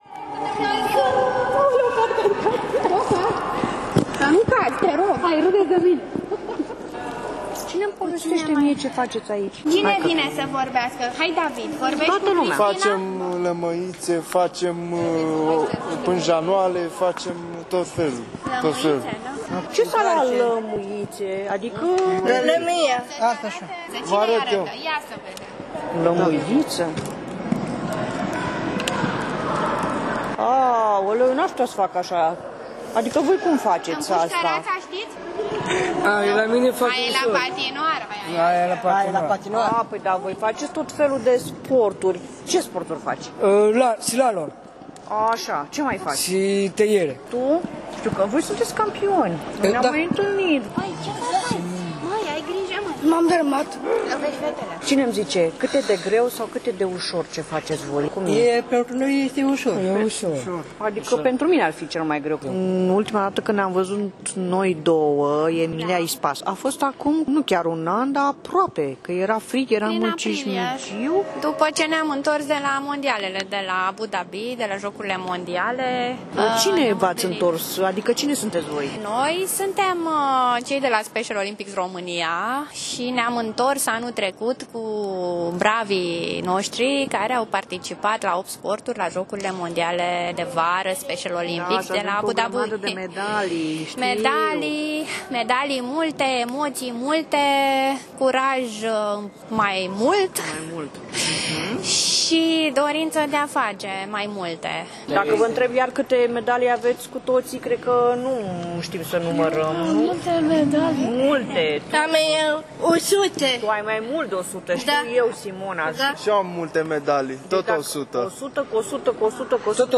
la ultimul antrenament de patinaj și a stat la povești cu ei, cu părinții și cu reprezentanții Fundației Special Olympics